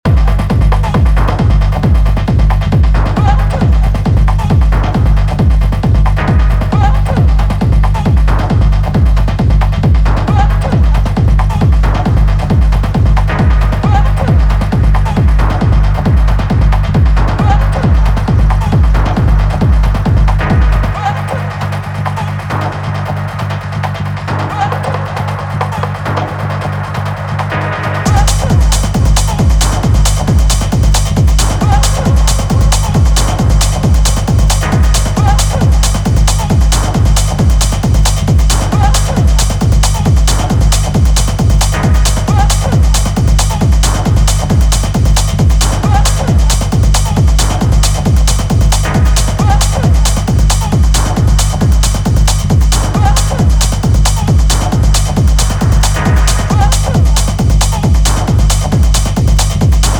touches your soul with a rolling drive